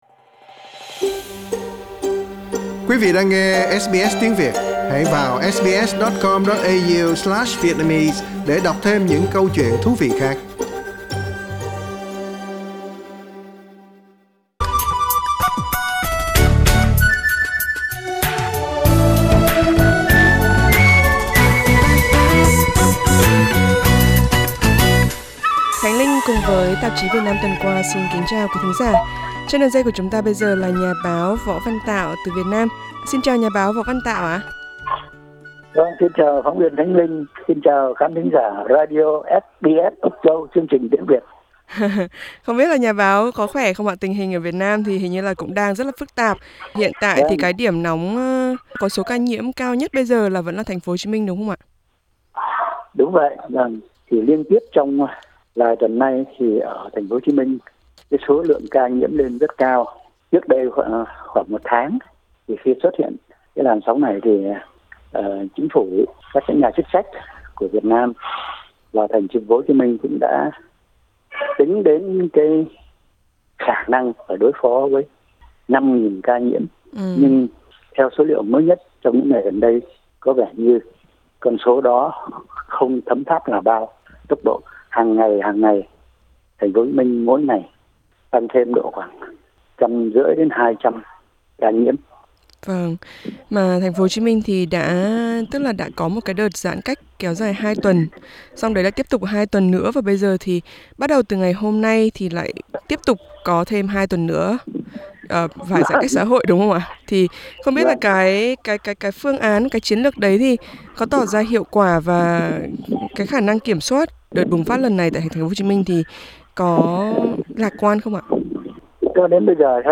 cuộc trao đổi